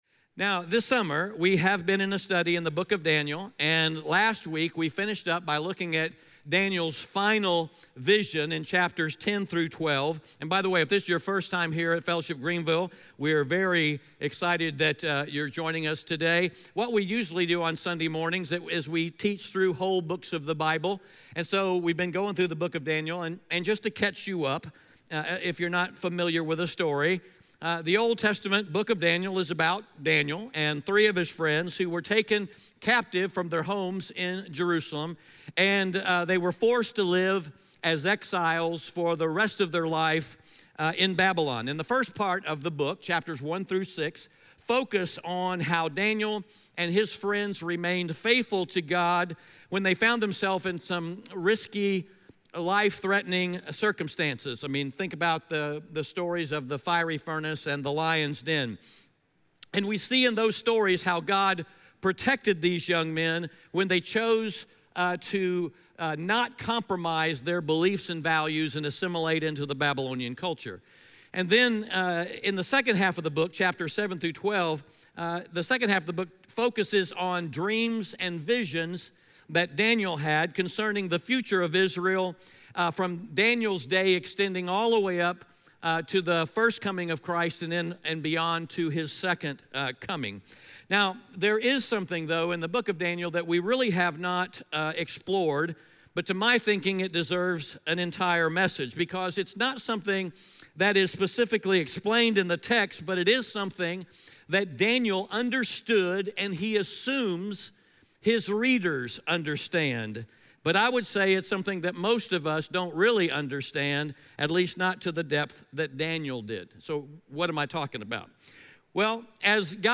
Audio Sermon Notes (PDF) Ask a Question Last week we finished up our study through the book of Daniel as we looked at Daniel’s final vision recorded for us in chapters 10-12.